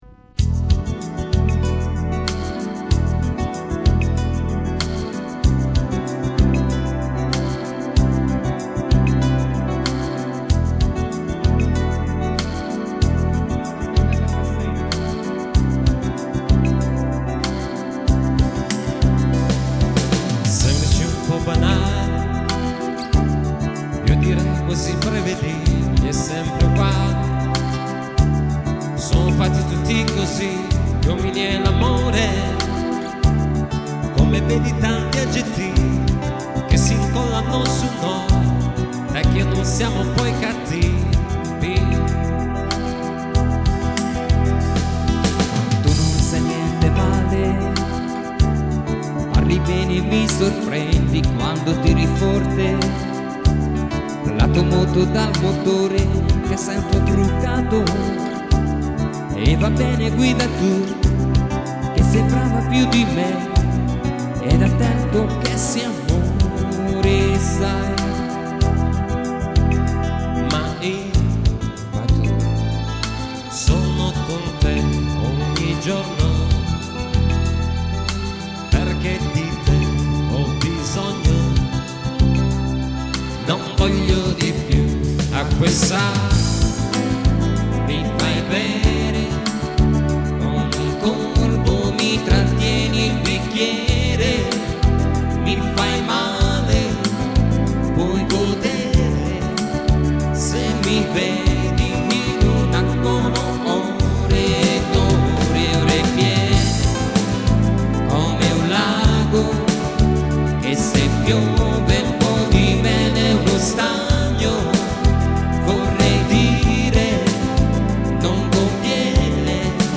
chitarra
tastiera e fisarmonica